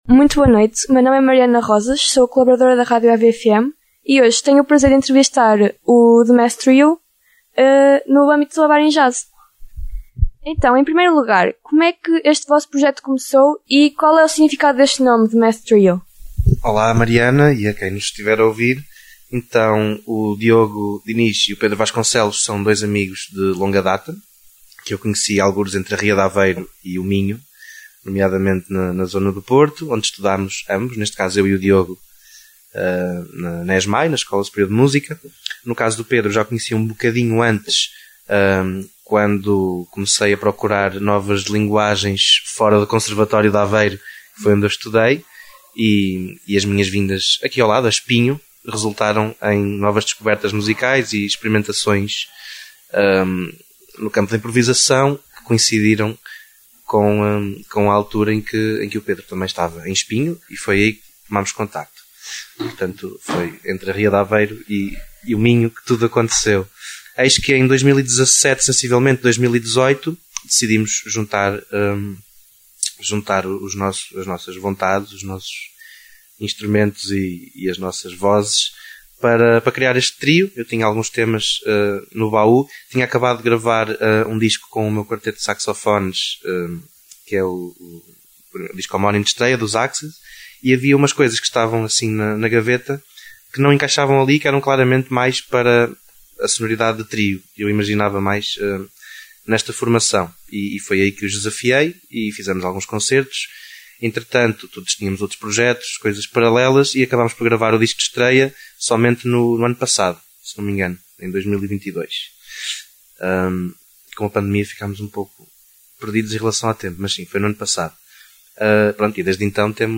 Deixo a entrevista realizada com